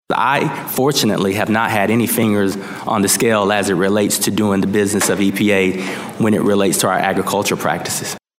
Environmental Protection Agency chief Michael Regan was called out on biofuel use, pesticide registrations, and the Biden Waters of the U.S. rule at a House Ag hearing.